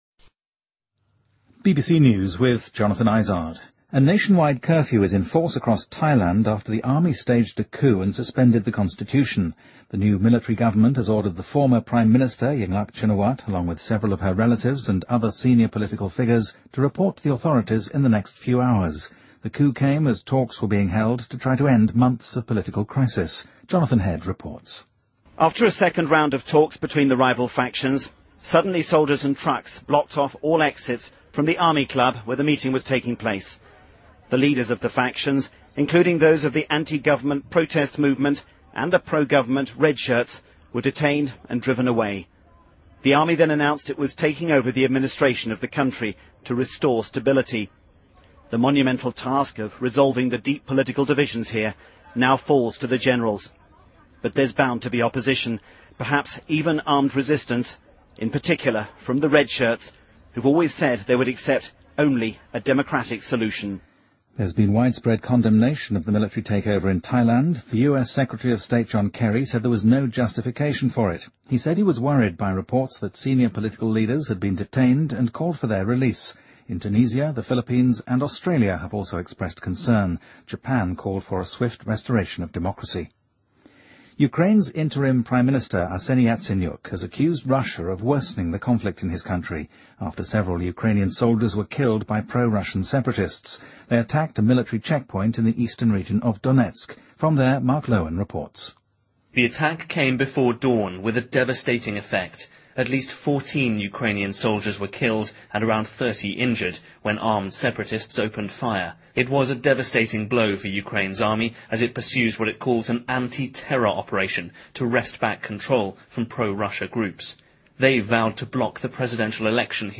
BBC news,泰国军队发动政变后实施宵禁